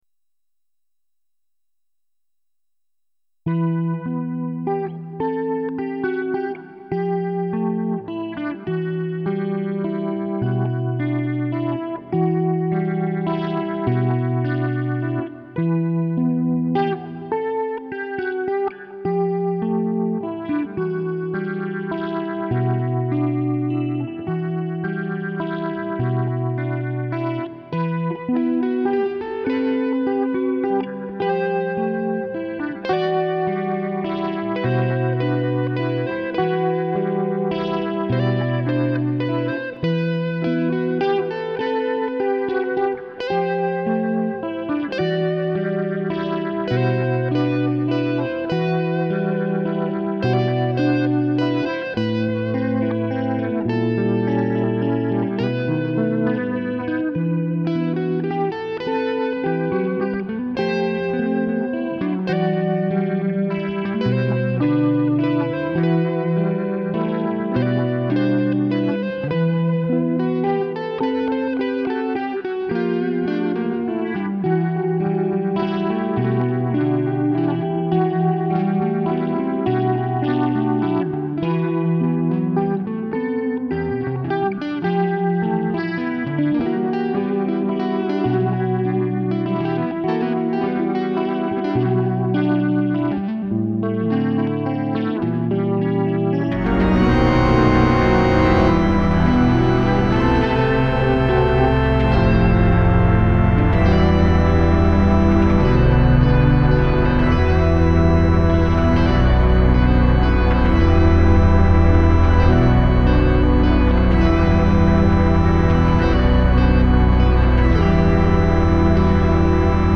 It’s pretty short and straight forward. Probably because it’s on the slow and introspective side – some would say the whiny crybaby side, but it is a little bit of a downer until the end, when it moves into hopelessness – some would say melodrama.
To me it sounds like sad circus music, or a sad person watching a circus, or a sad person no longer watching the circus, or a sad person drinking at home next to a telephone they didn’t have the courage to use while a circus plays on the TV in the other room.
It’s 3 live guitar tracks recorded into Sonar and two synthed organ tracks – one of which is just an organ patch from the soundcard in my PC. The hard right organ sound is from Reason.